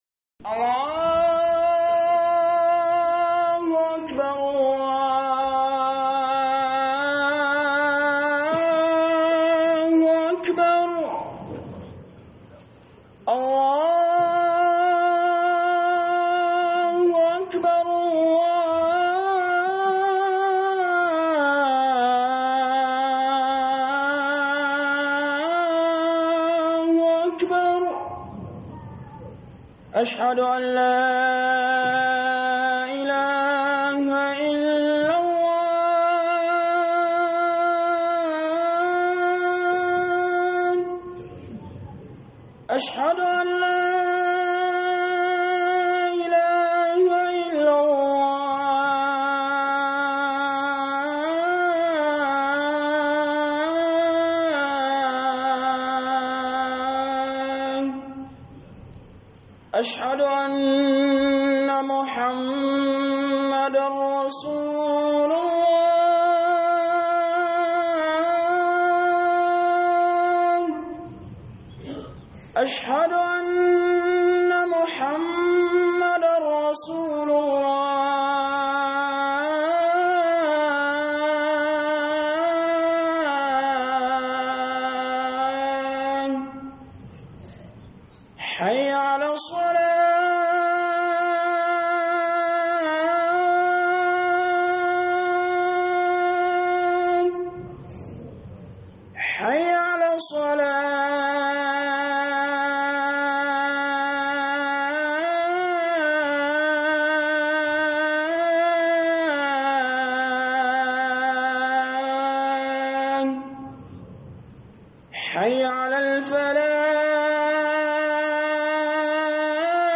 AURE A MUSULUNCI - Huduba